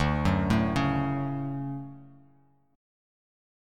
DM7 Chord
Listen to DM7 strummed